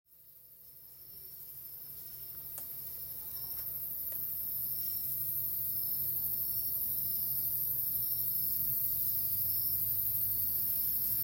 La noche ALAJUELA